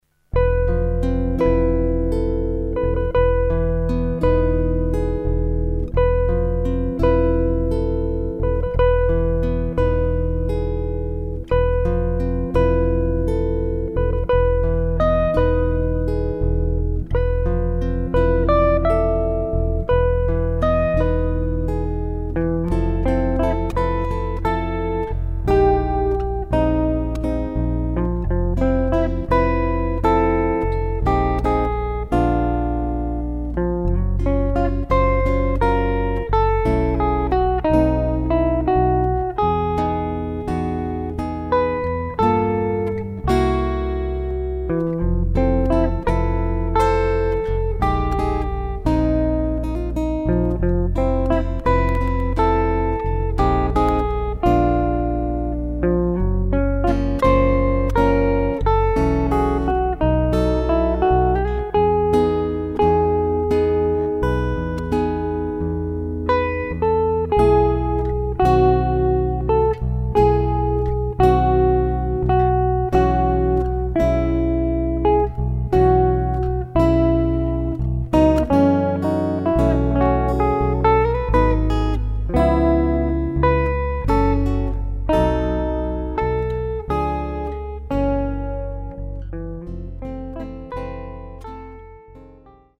Chitarre e basso